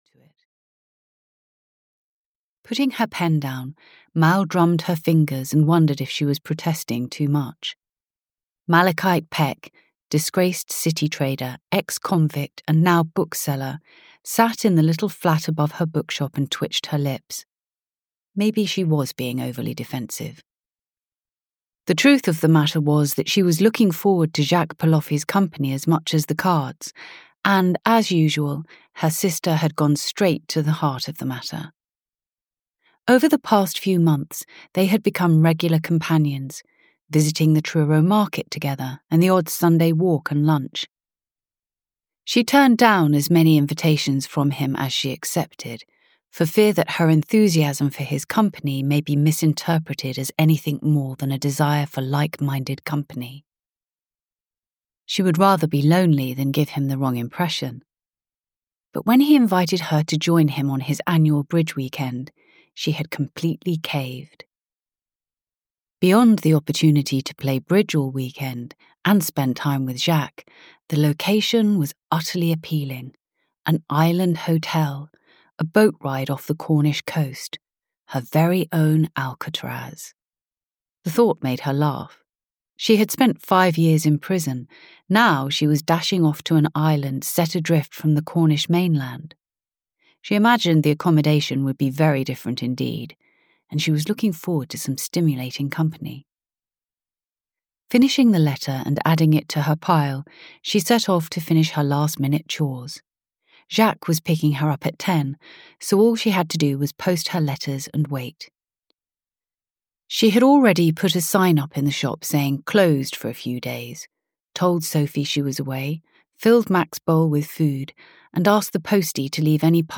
Death at Castle Wolf (EN) audiokniha
Ukázka z knihy
• InterpretNatasha Little